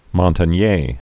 (mŏntən-yā), Luc Born 1932.